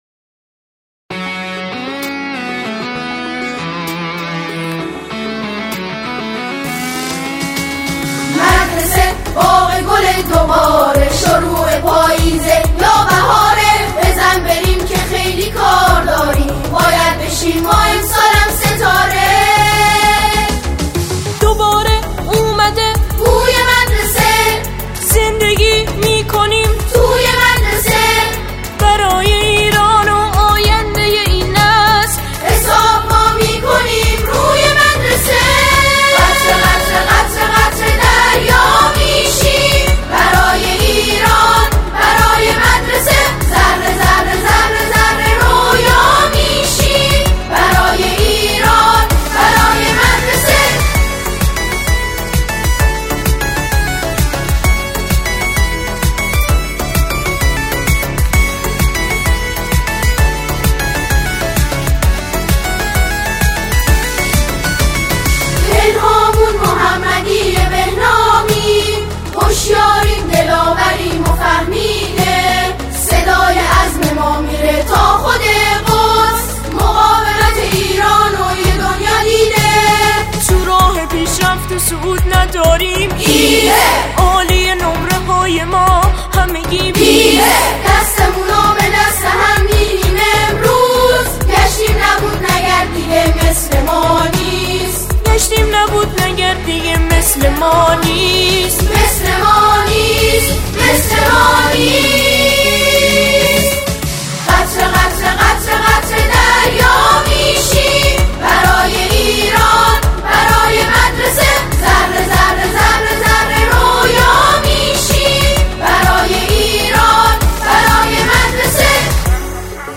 سرودهای دانش آموزی